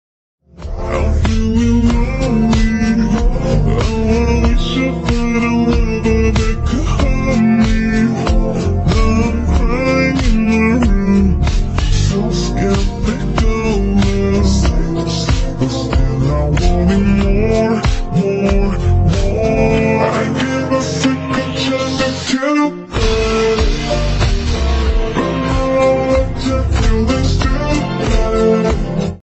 AI Horror